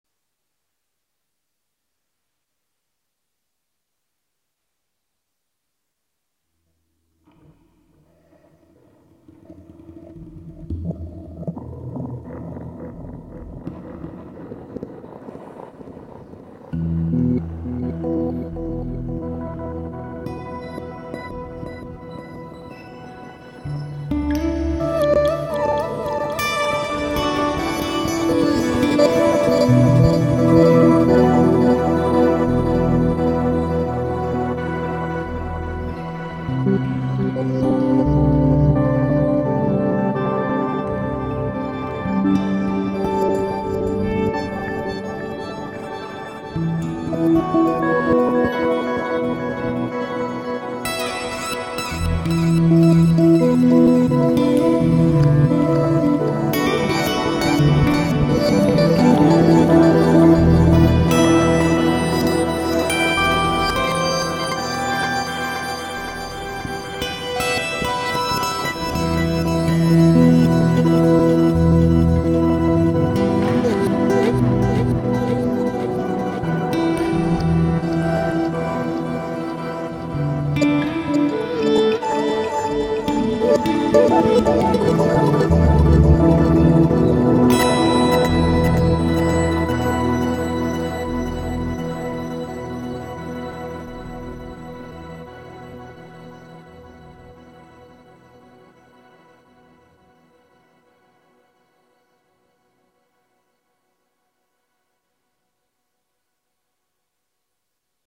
• Guitar straight in
Compressor / Reverb
Buffer 1 and 2, pitch +12, Rate -64
Phaser / Delay